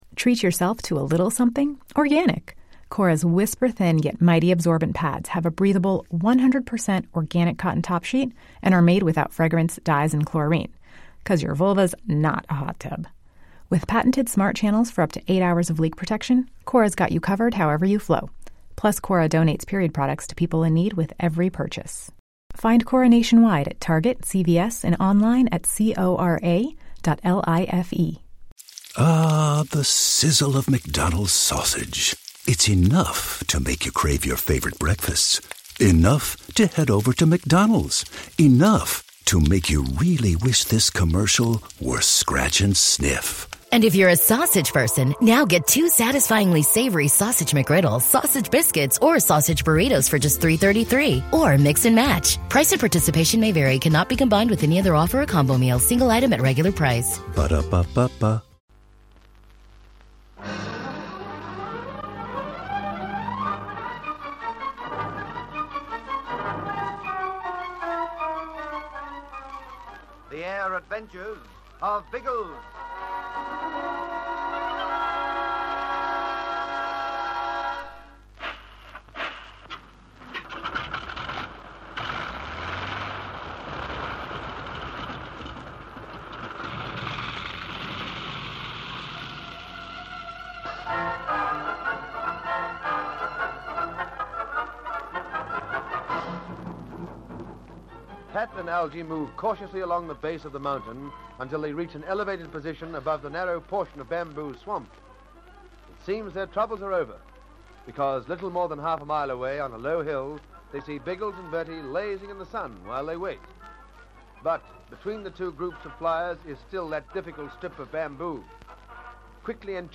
The Air Adventures of Biggles was a popular radio show that ran for almost a decade in Australia, from 1945 to 1954. It was based on the children's adventure novels of the same name by W.E. Johns, which chronicled the exploits of Major James Bigglesworth, a World War I flying ace who continued to have thrilling adventures in the years that followed.